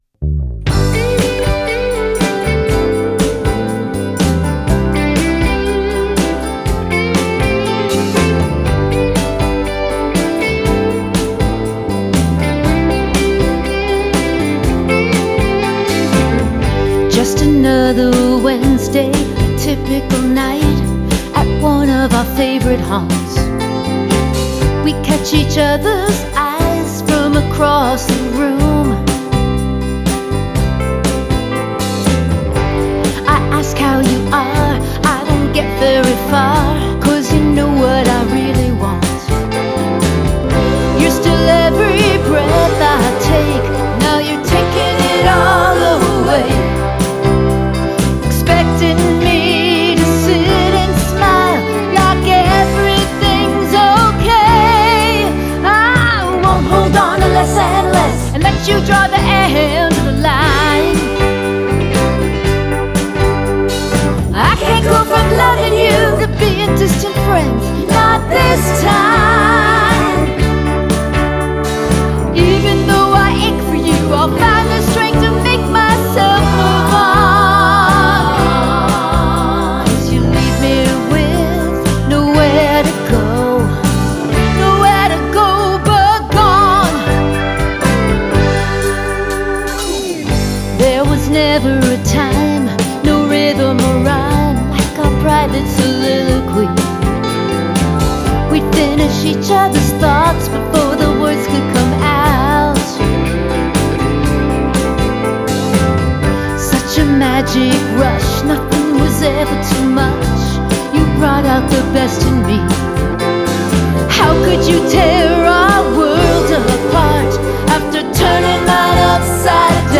soulful country/rock song
powerful up-tempo song